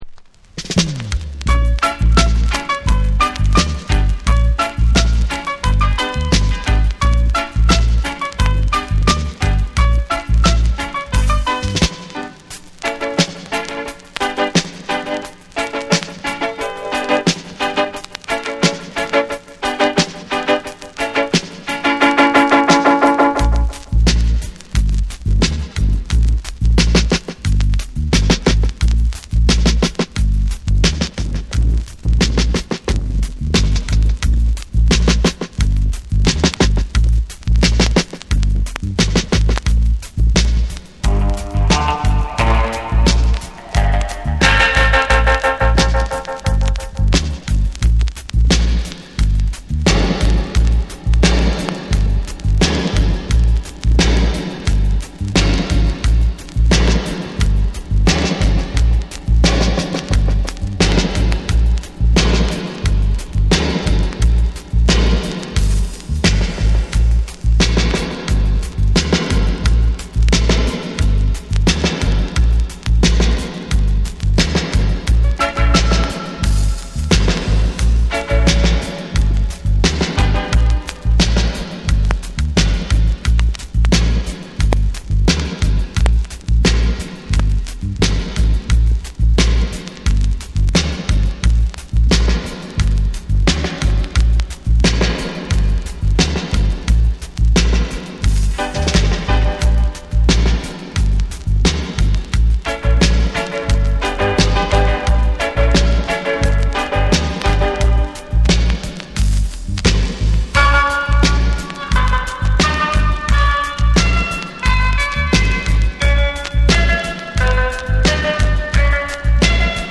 力強くリリカルな歌声が響く
ヘヴィーで土臭いグルーヴを堪能できる名盤シングル。